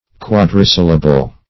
Search Result for " quadrisyllable" : The Collaborative International Dictionary of English v.0.48: Quadrisyllable \Quad`ri*syl"la*ble\, n. [Quadri- + syllable: cf. F. quadrisyllabe.] A word consisting of four syllables.